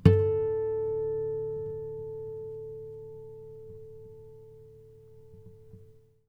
strings_harmonics
harmonic-04.wav